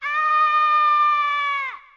Play Toad Aaaaaaah - SoundBoardGuy
Play, download and share Toad aaaaaaah original sound button!!!!